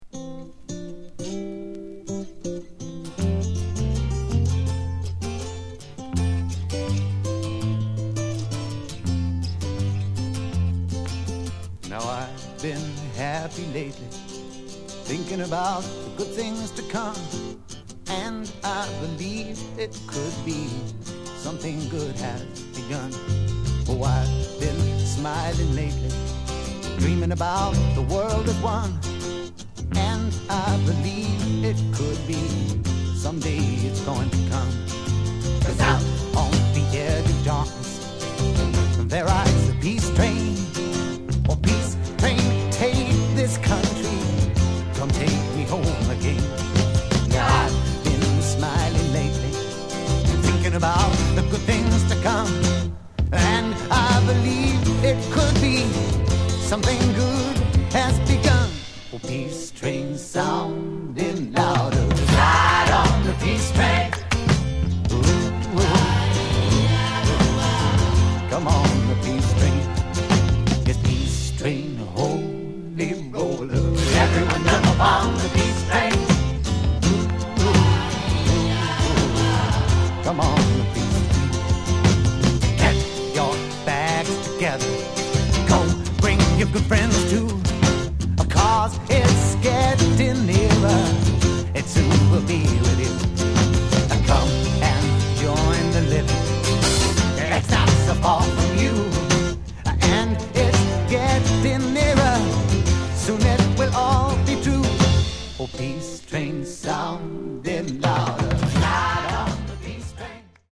Genre: Folk Rock
Top-notch Folk Rock!